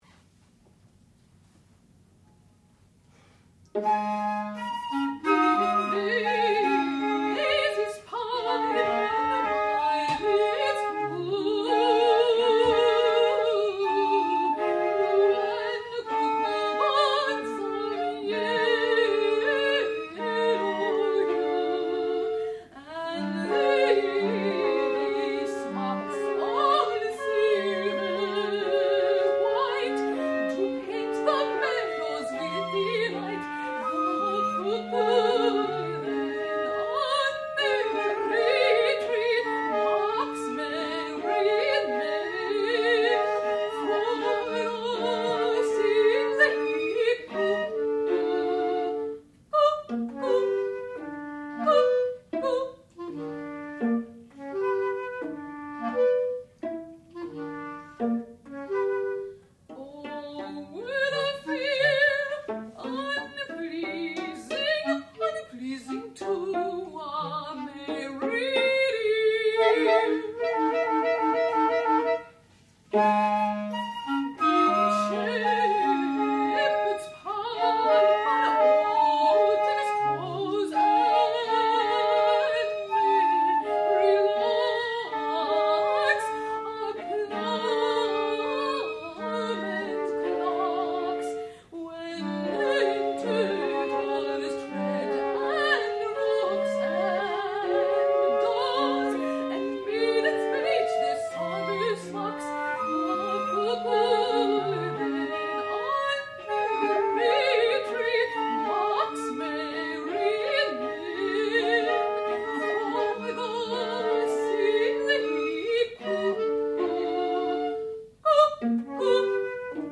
sound recording-musical
classical music
clarinet
flute
viola